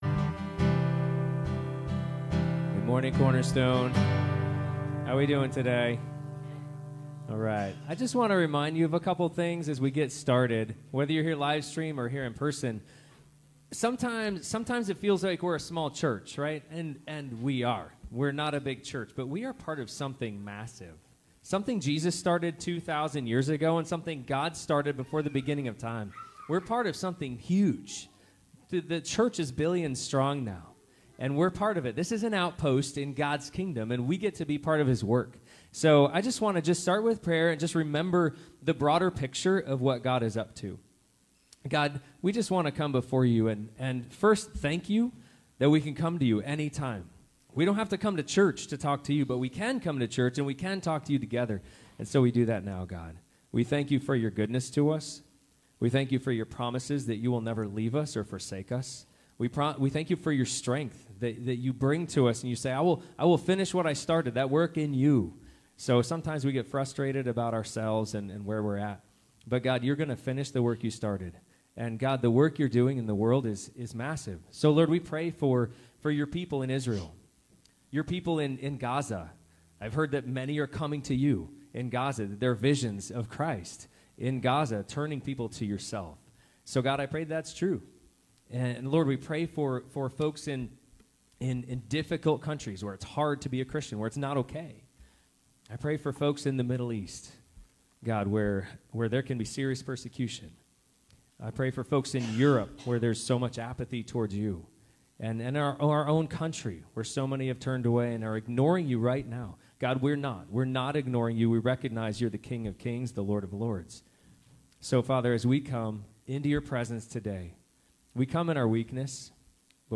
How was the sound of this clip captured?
Passage: Genesis 2 Service Type: Sunday Morning